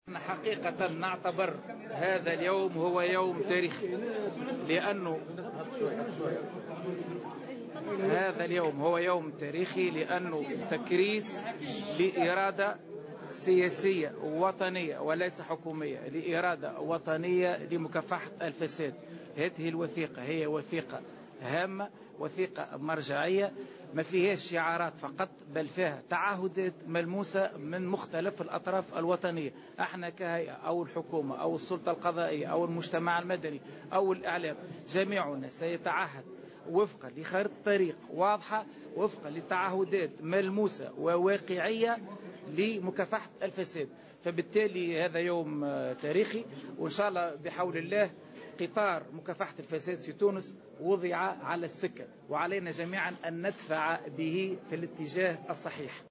وأضاف في تصريح لمراسل "الجوهرة أف أم" أن هذه الوثيقة تعتبر هامة ومرجعية وتتضمن تعهّدات ملموسة من مختلف الأطراف الوطنية بمكافحة الفساد.